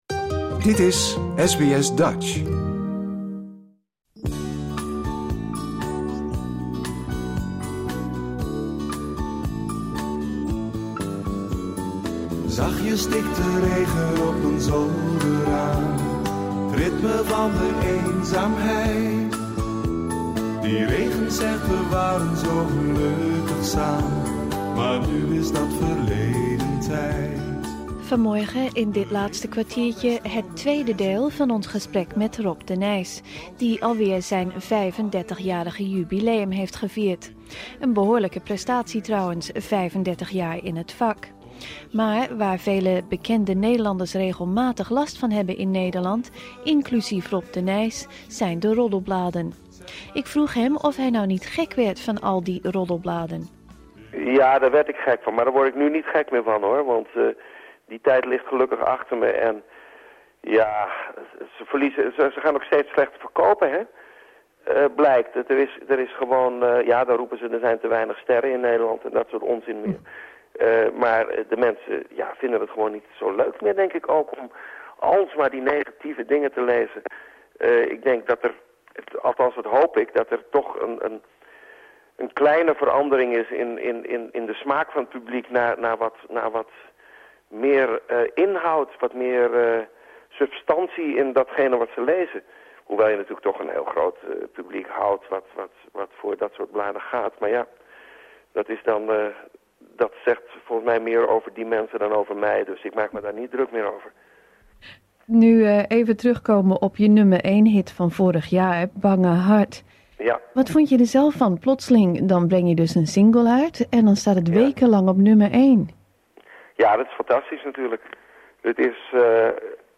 SBS Dutch interview Rob de Nijs deel 2